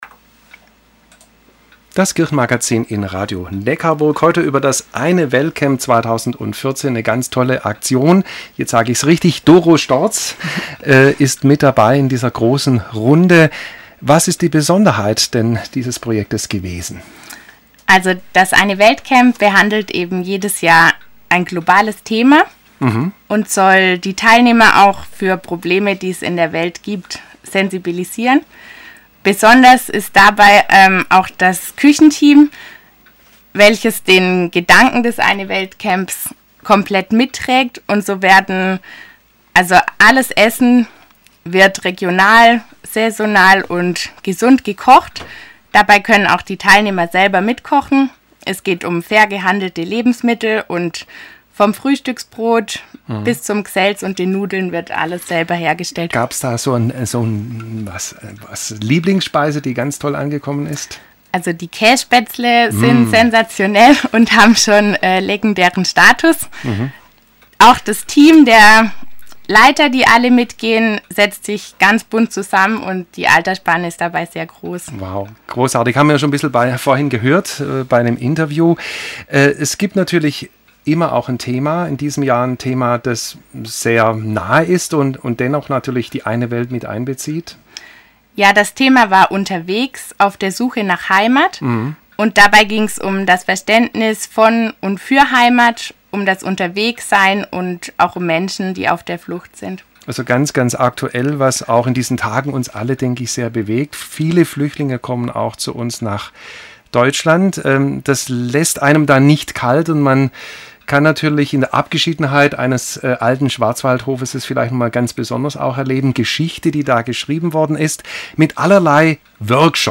Radiosendung | Alianza – Partnerschaft